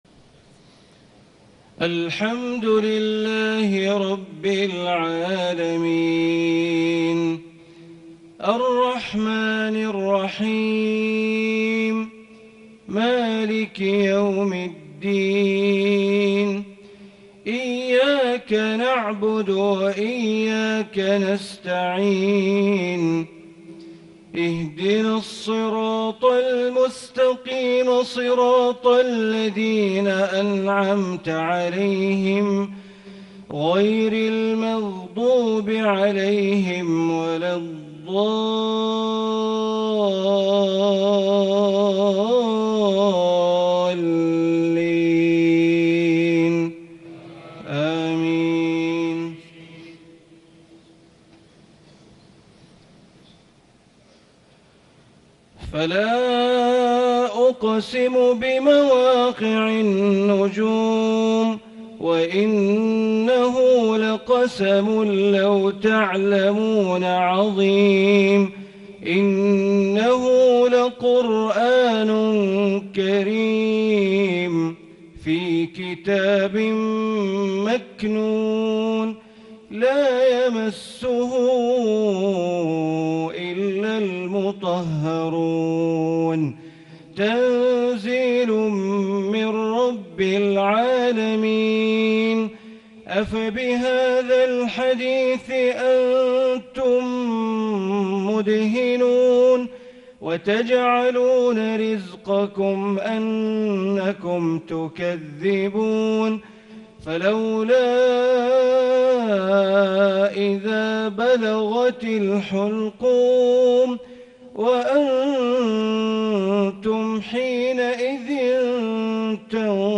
١١١ > 1438 > Prayers - Bandar Baleela Recitations